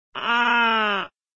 SSecurityAlarm.ogg